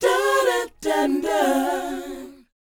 DOWOP G#4F.wav